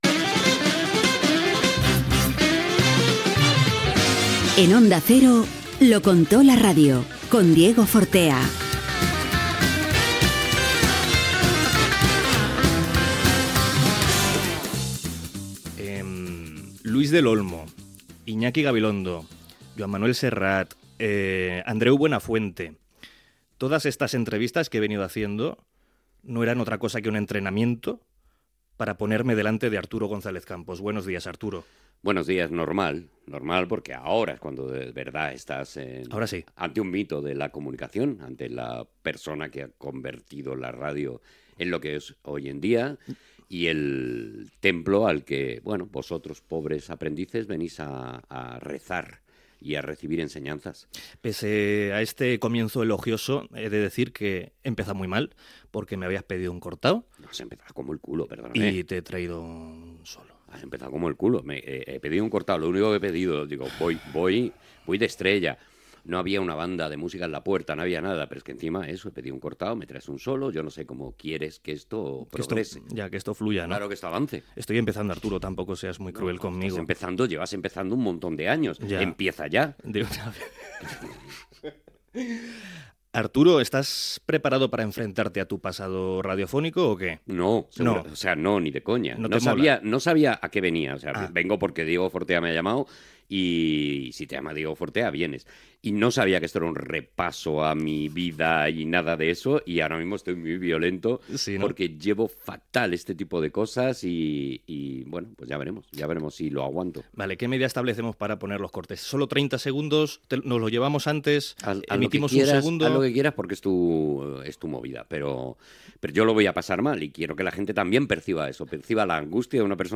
Careta del programa, presentació i entrevista al periodista i humorista Arturo González Campos, sobre el seu passat radiofònic.
Entreteniment